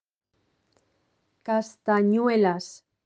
Audio file of the word "Castanets"